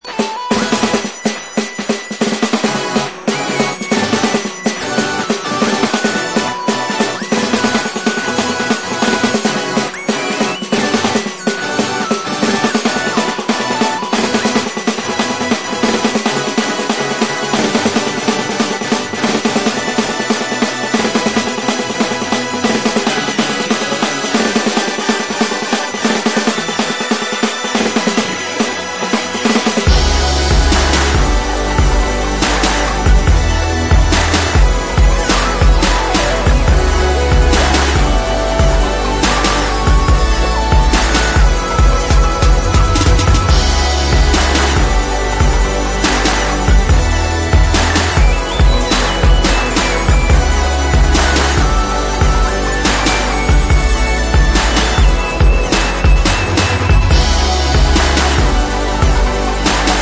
Electro Electronix Hip Hop